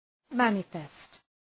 Shkrimi fonetik {‘mænə,fest}